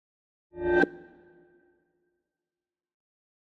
meleeattack-swoosh-magicaleffect-group01-psychic-00.ogg